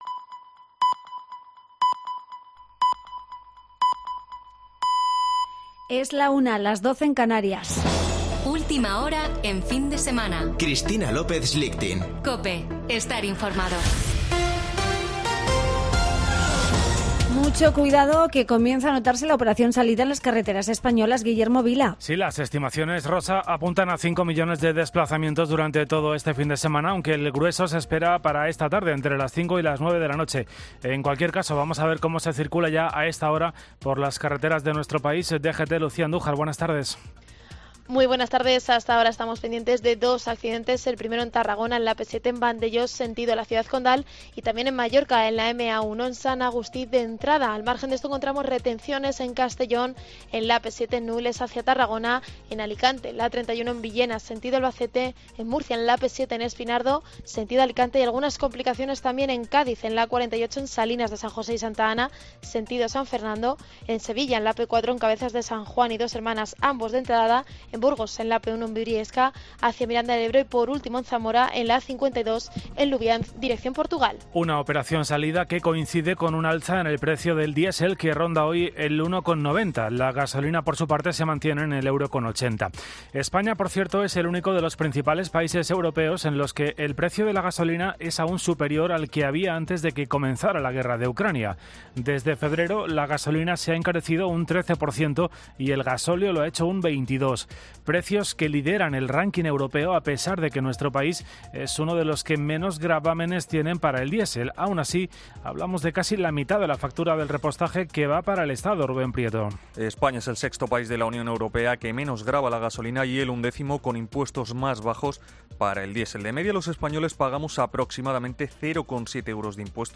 Boletín de noticias de COPE del 28 de agosto de 2022 a la 13.00 horas